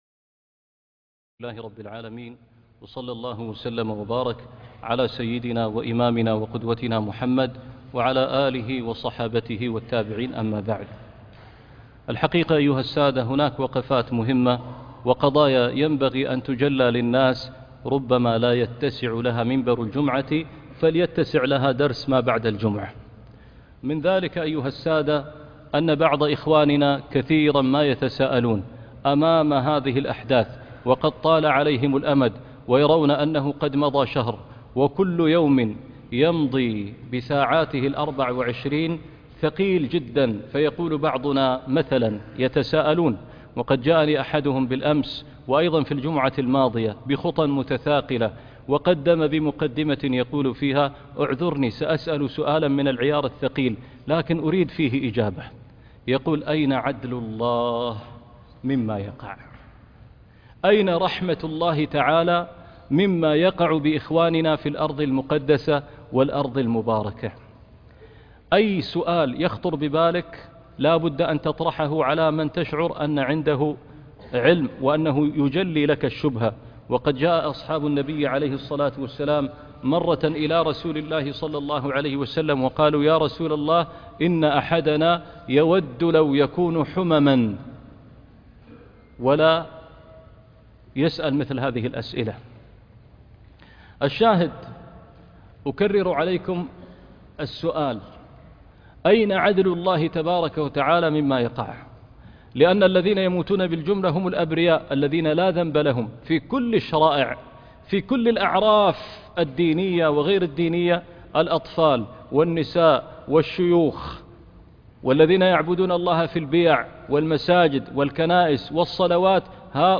سؤالات الطوفان - خطبة الجمعة